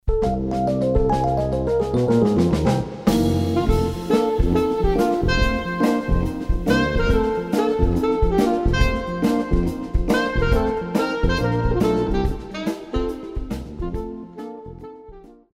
Classical
Saxophone-Alto
Band
Classics,Jazz,Classical Music,Classical Rearrangement
Instrumental
Only backing